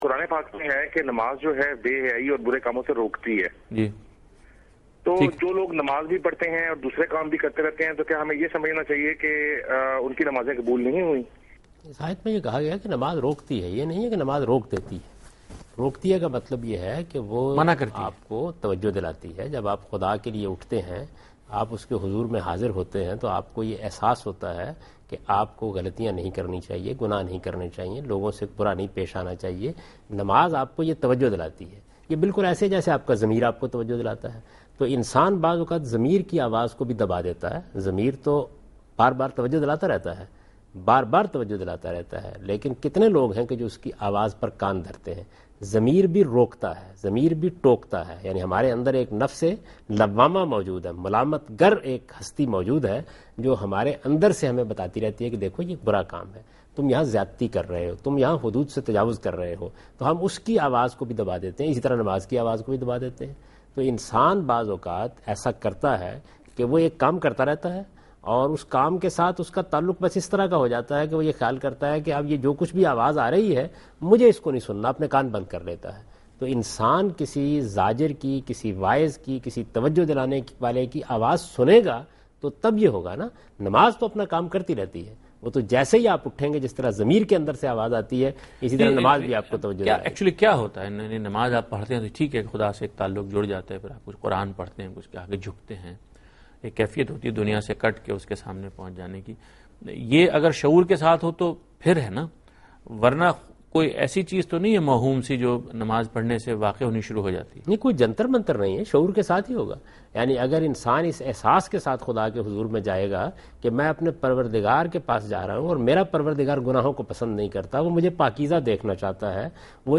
Category: TV Programs / Dunya News / Deen-o-Daanish / Questions_Answers /
دنیا نیوز کے پروگرام دین و دانش میں جاوید احمد غامدی ”نماز اور برے کام“ سے متعلق ایک سوال کا جواب دے رہے ہیں